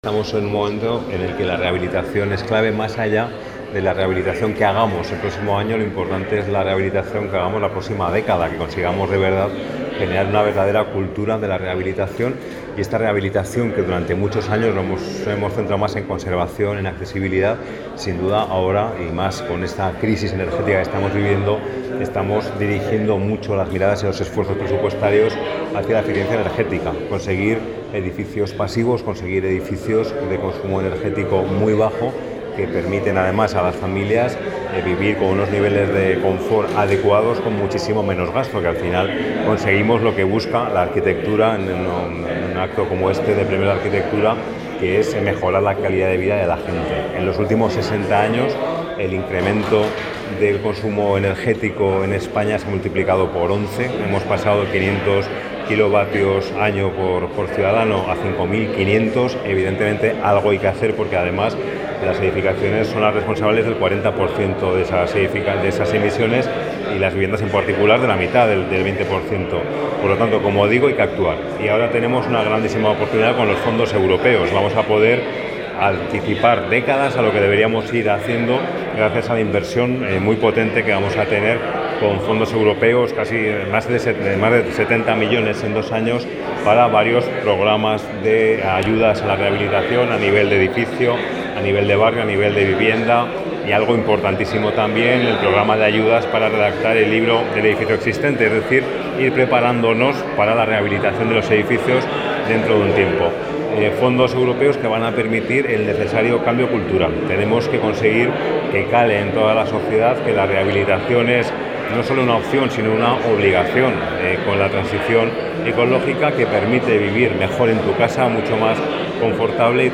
El consejero de Vertebración del Territorio, Movilidad y Vivienda, José Luis Soro, ha asistido a la celebración de los XXXVII Premios de la Arquitectura «Fernando García Mercadal» organizados por el Colegio Oficial de Arquitectos en Zaragoza.